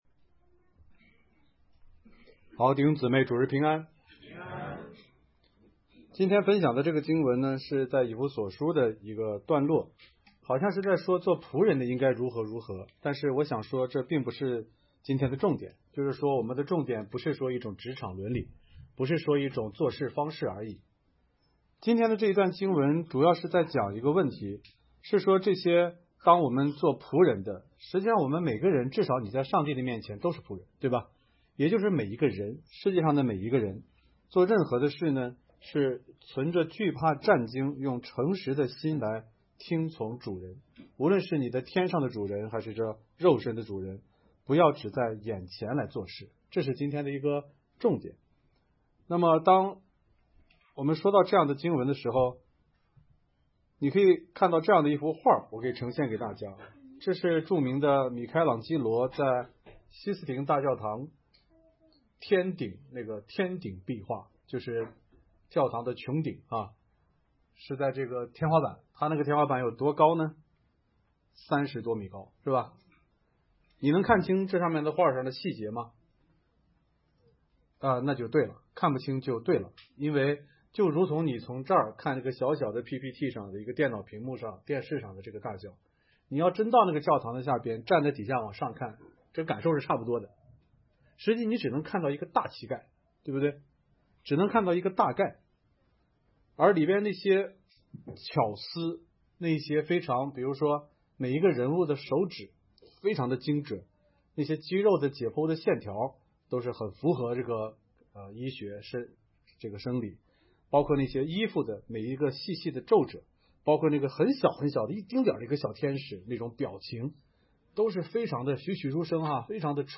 讲章